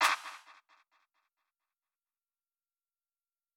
SIZZLE1SNARE.wav.wav